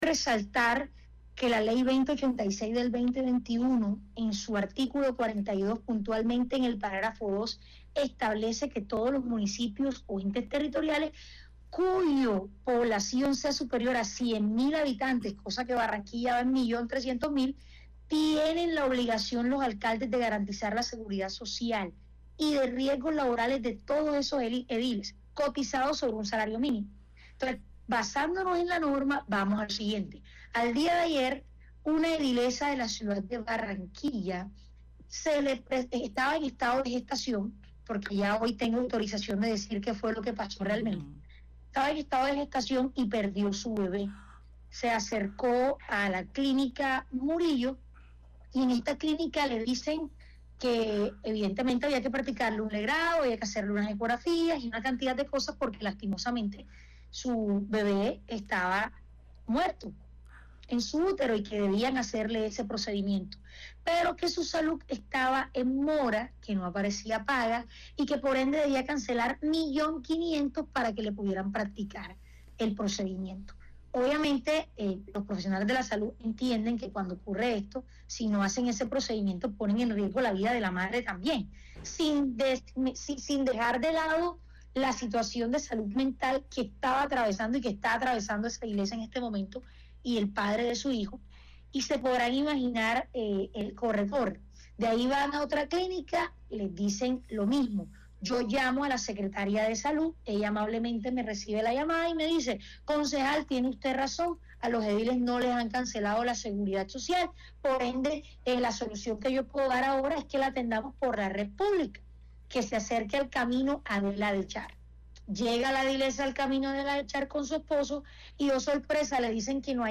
heidy-barrera-ENTREVISTA.mp3